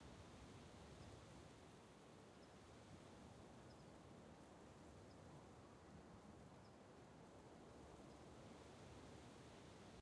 sfx-jfe-amb-loop-3.ogg